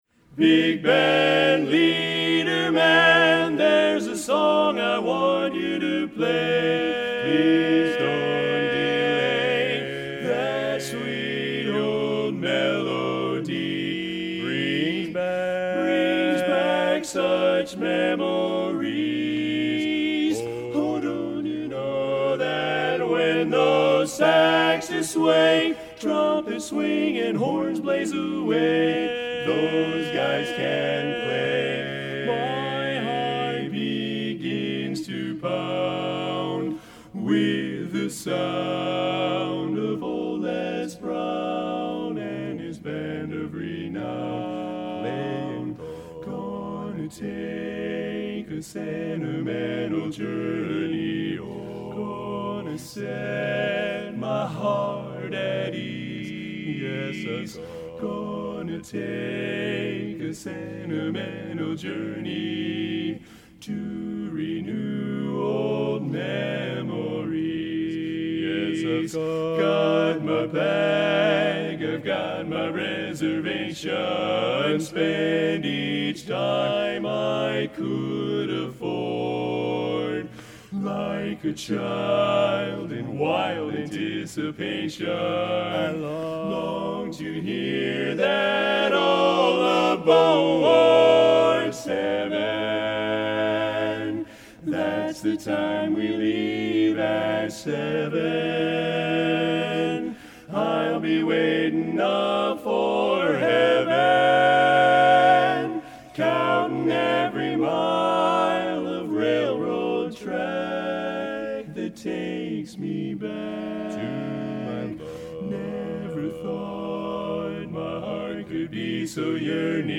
2009 FWD Champions